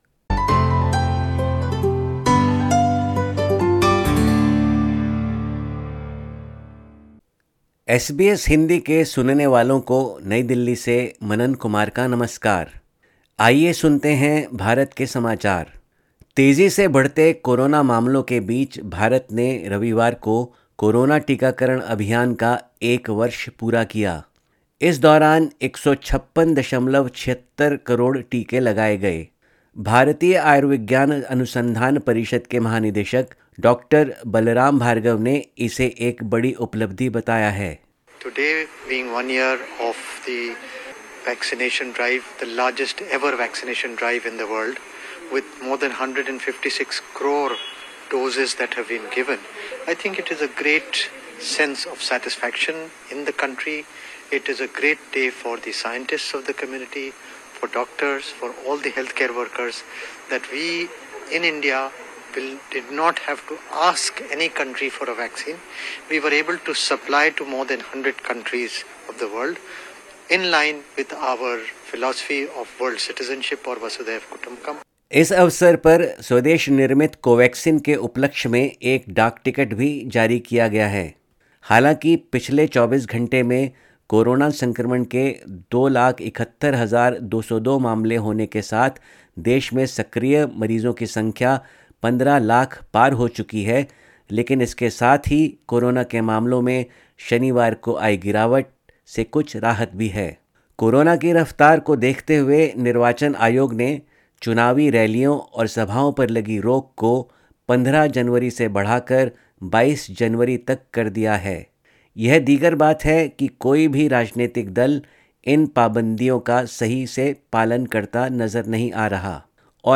17_jan_22_sbs_hindi_india_news_bulletin.mp3